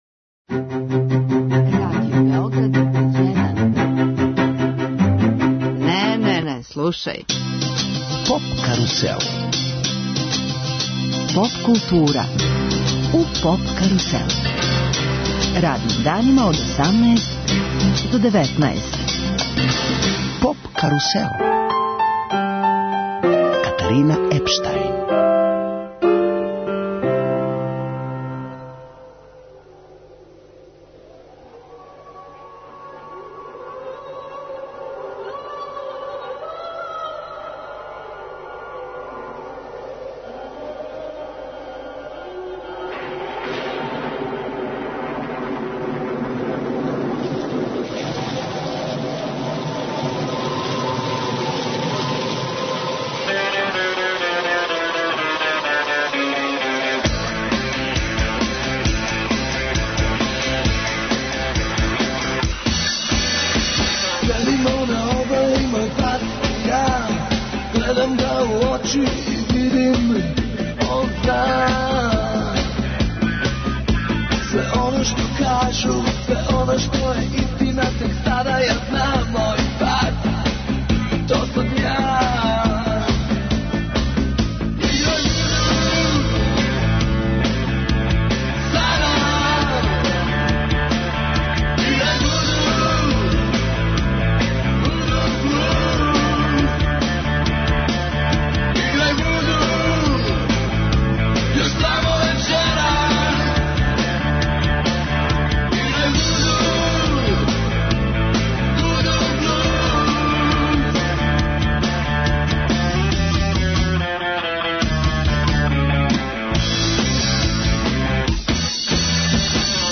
Пре пуних 30 година Електрични оргазам издао је албум 'Дисторзија'. Јубилеј ће бити обележен 23. децембра у Дому омладине Београда, а тим поводом наш гост је Срђан Гојковић Гиле.
Емисија из домена популарне културе.